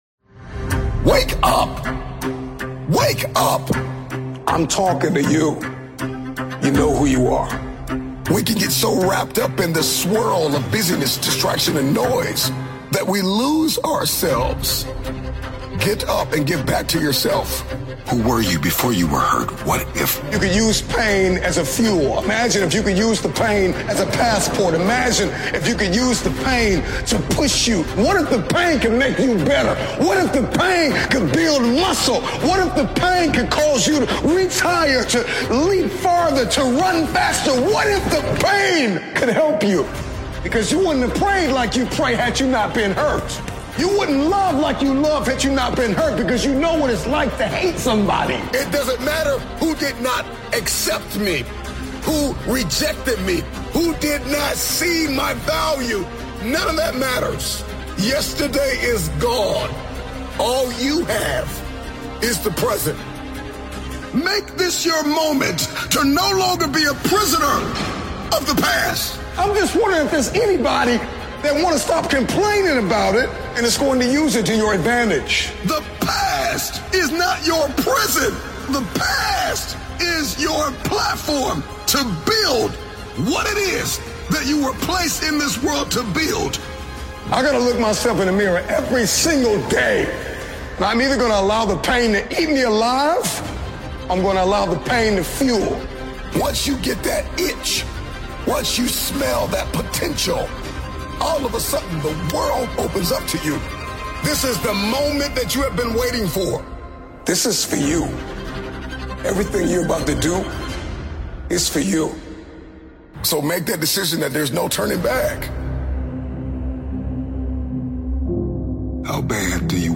motivational speech compilation